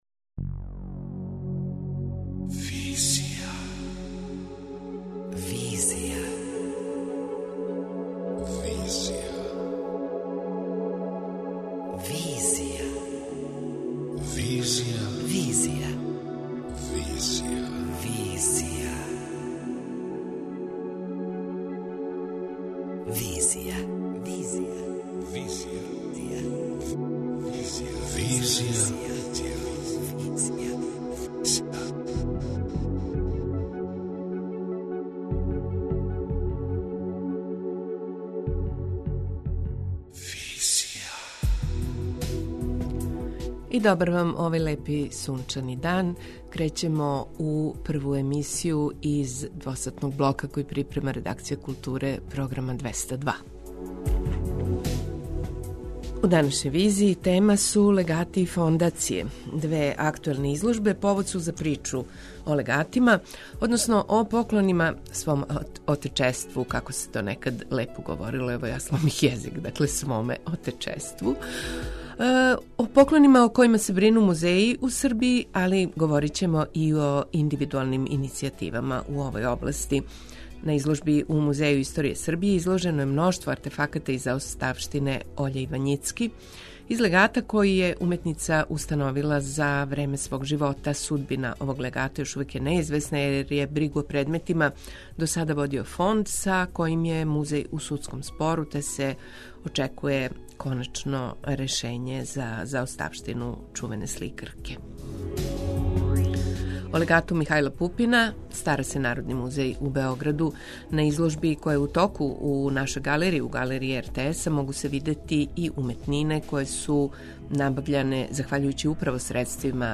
преузми : 26.62 MB Визија Autor: Београд 202 Социо-културолошки магазин, који прати савремене друштвене феномене.